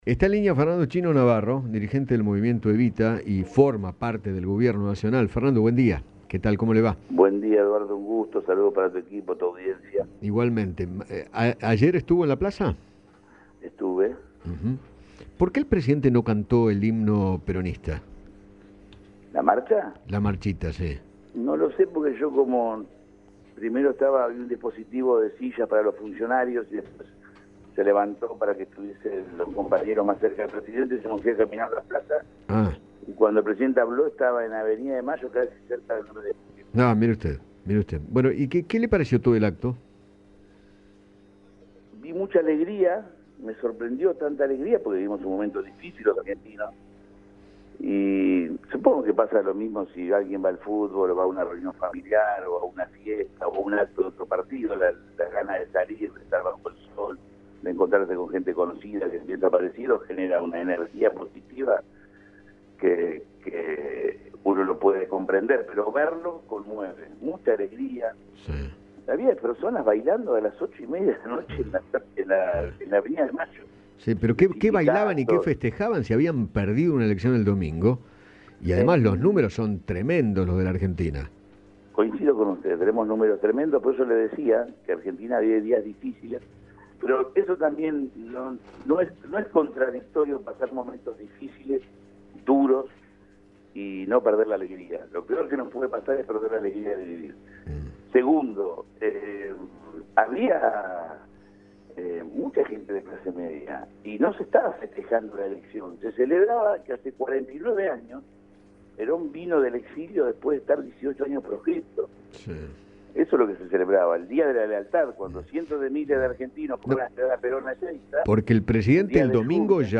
Fernando ‘Chino’ Navarro, dirigente del Movimiento Evita, conversó con Eduardo Feinmann sobre los festejos por el Día de la Militancia, en Plaza de Mayo, y opinó acerca de lo que debe mejorar el Gobierno para las próximas elecciones.